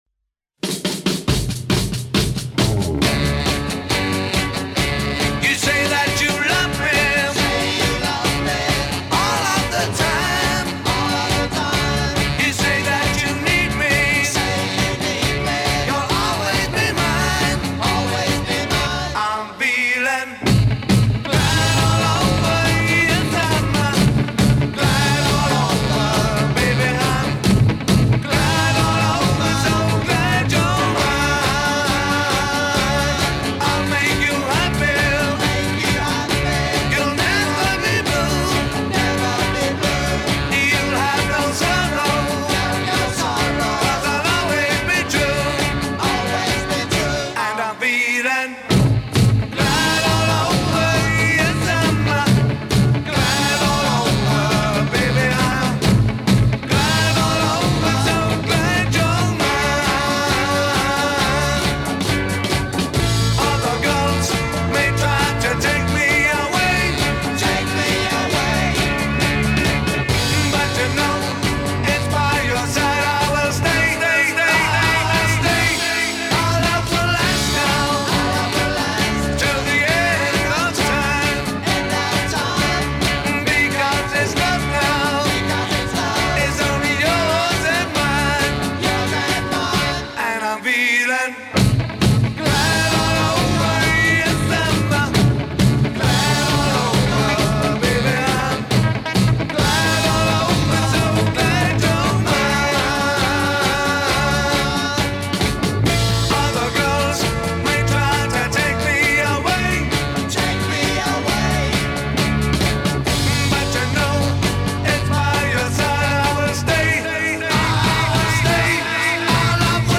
бит
поп-рок